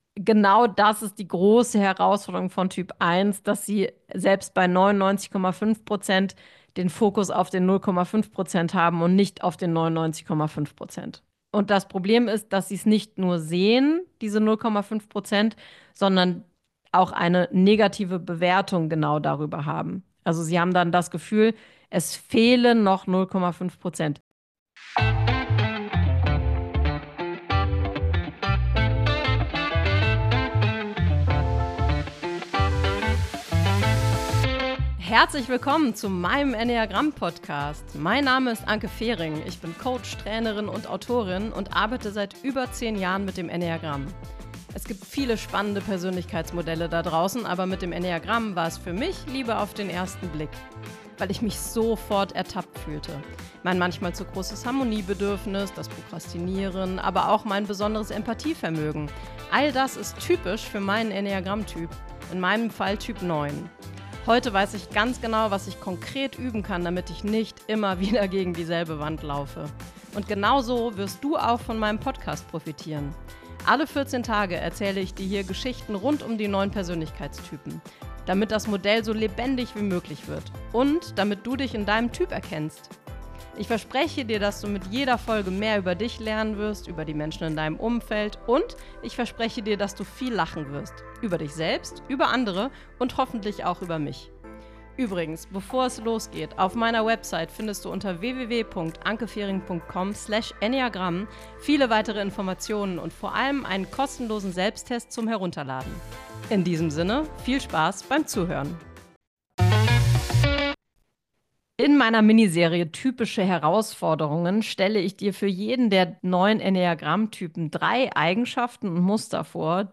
Solofolge_Herausforderungen_Typ_1.mp3